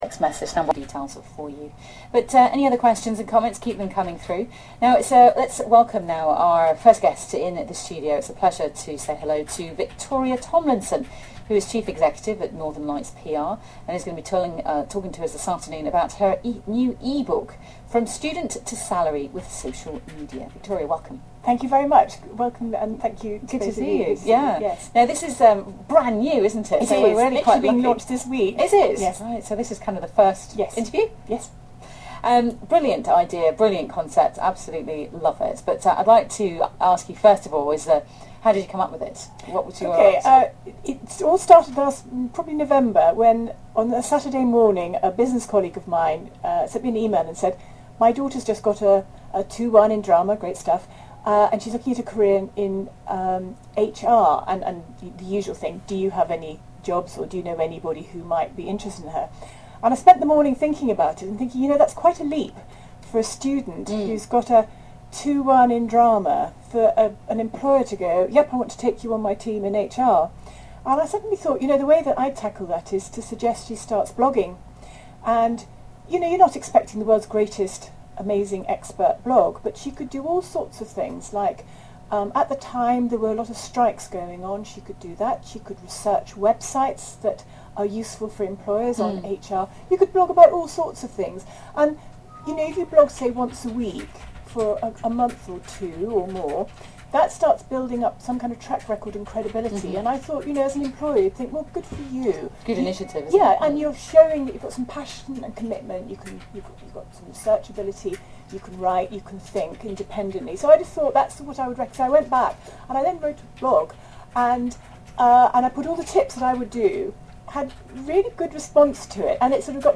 Click on the links below Interview 1 Interview2